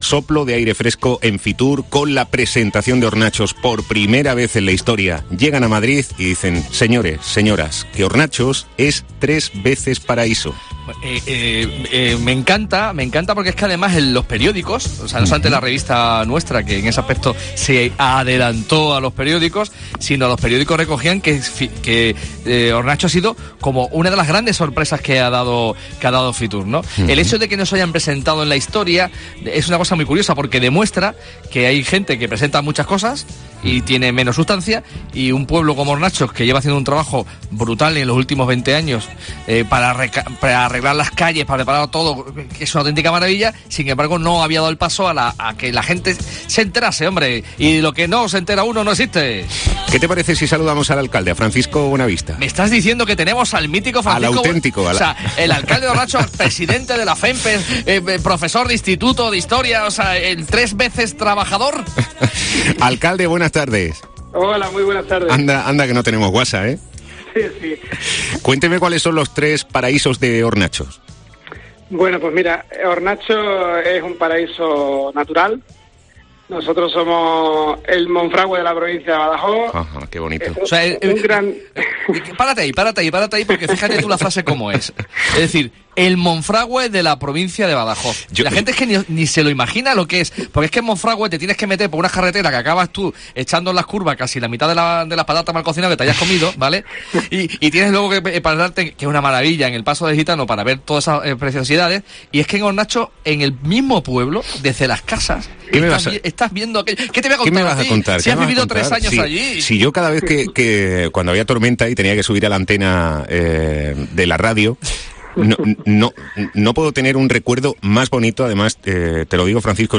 AUDIO: Los ha desvelado en 'A Cuerpo de Rey' su alcalde, Francisco Buenavista